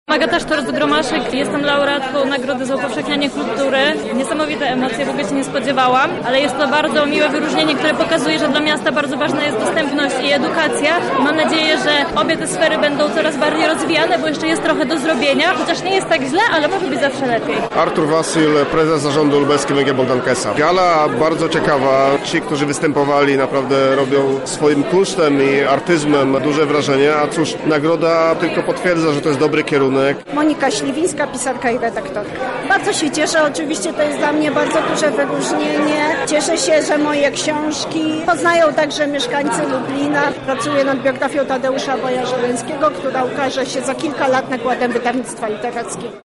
O wrażeniach z Gali porozmawialiśmy ze zwycięzcami: